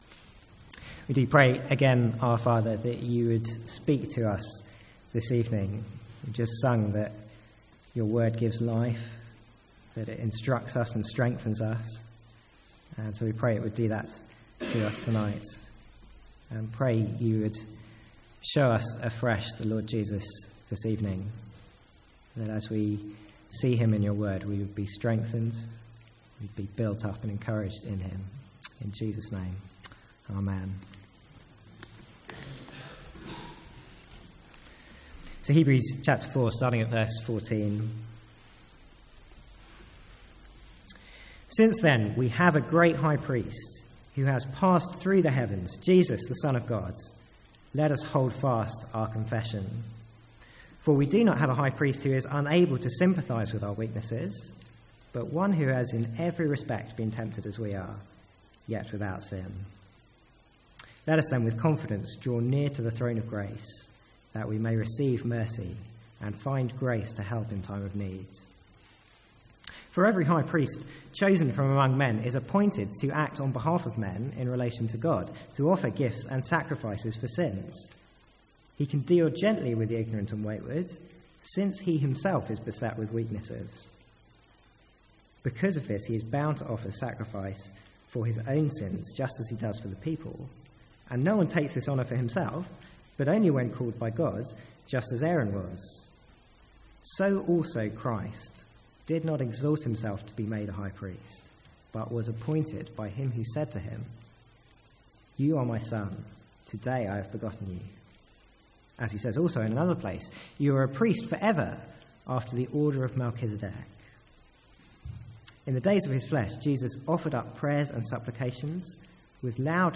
From the Sunday evening series in Hebrews.